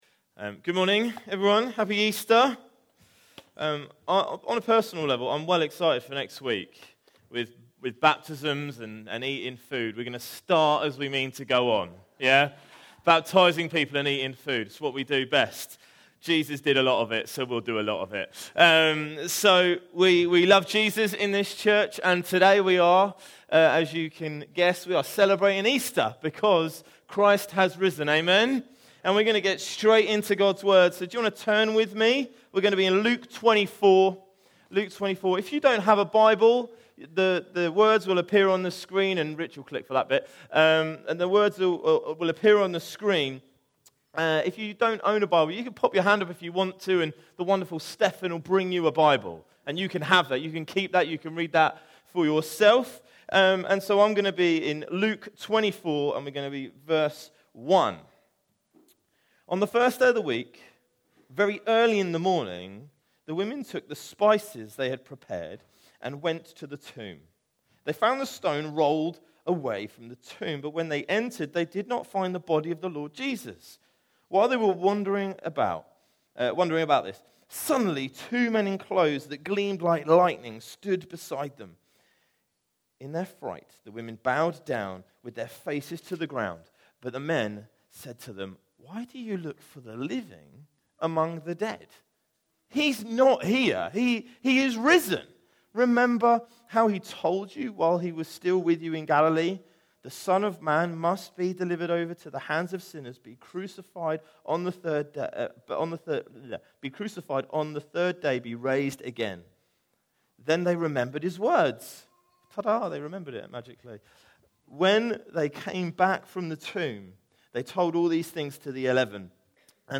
Series: Other Sermons 2025